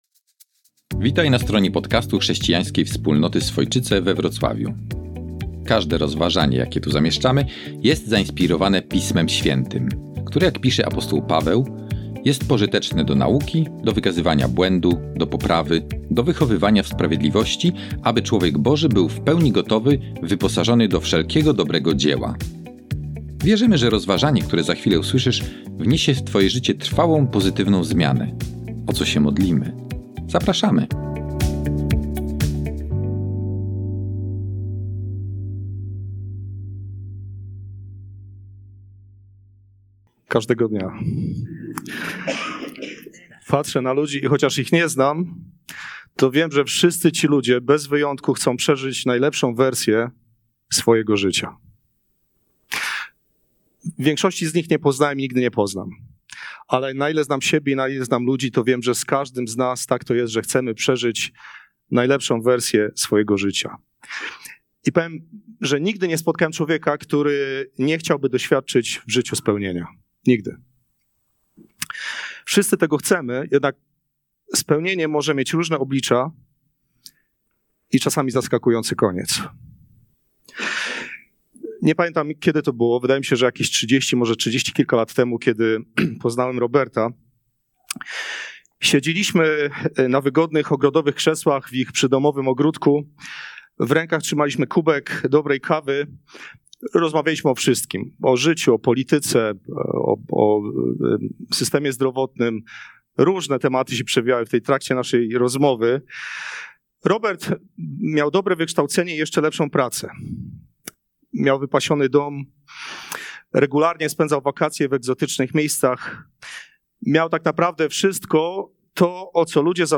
Nauczanie z dnia 1 grudnia 2024